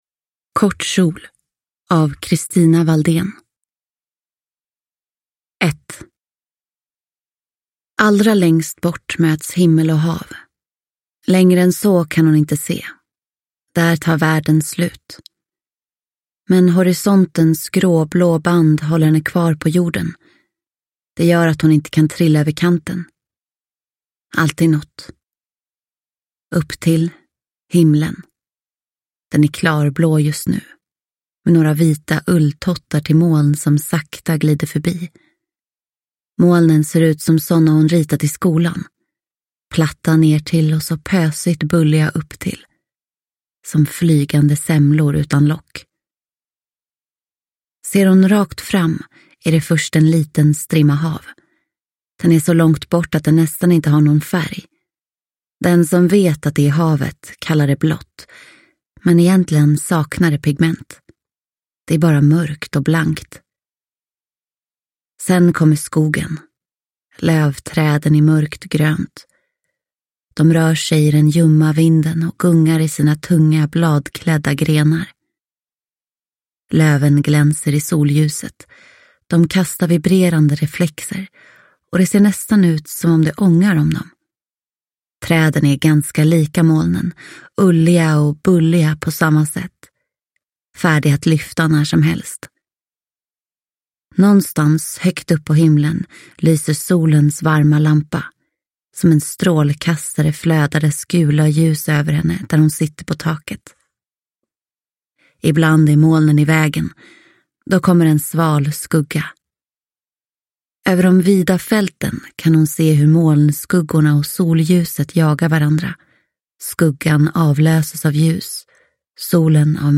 Kort kjol – Ljudbok